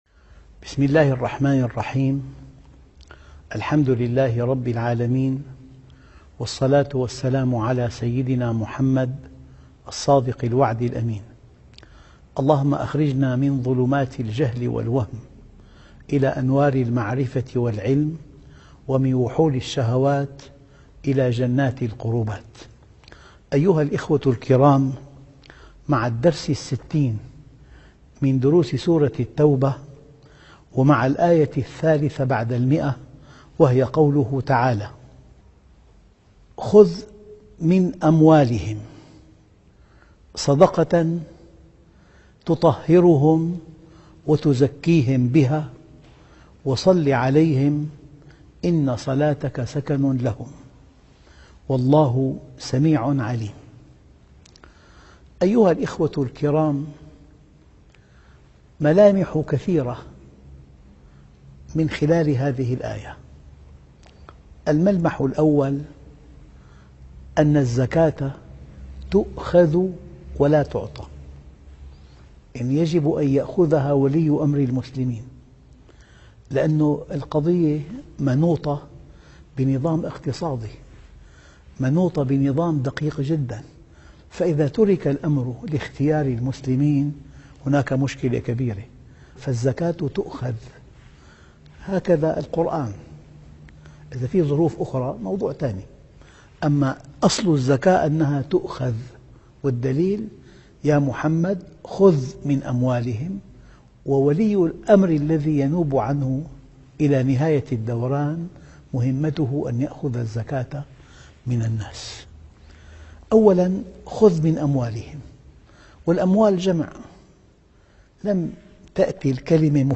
الدرس ( 60) تفسير سورة التوبة